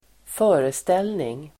Uttal: [²f'ö:restel:ning]